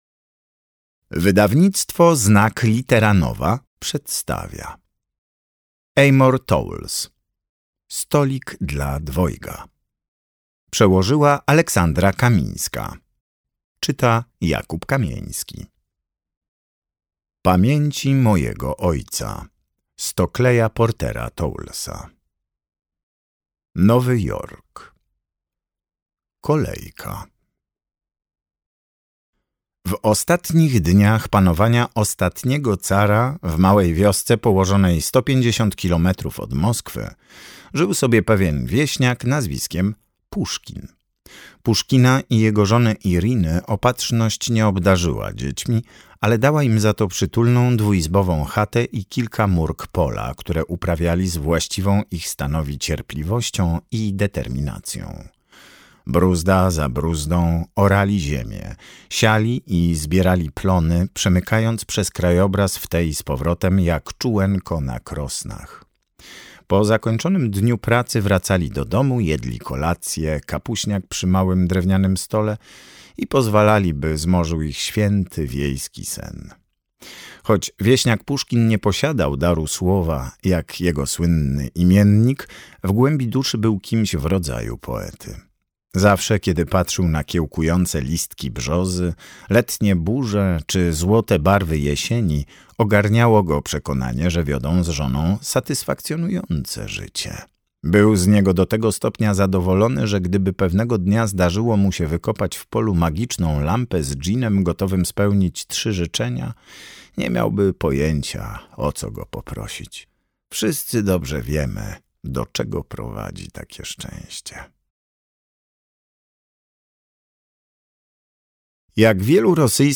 Stolik dla dwojga - Amor Towles - audiobook